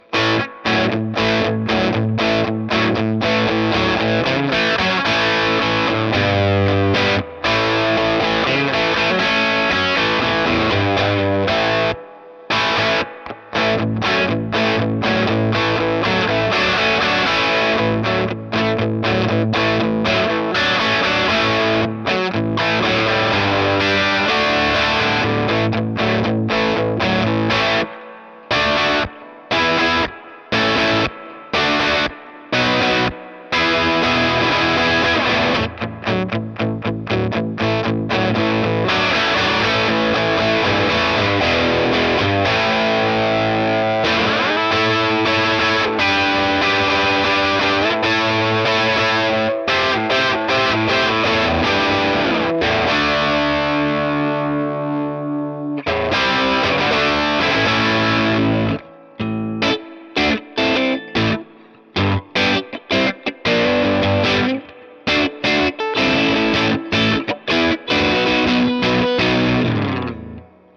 REC: Rode NT1 and a Sure sm57 into Cubase | No effects added.
rockNriffn1.mp3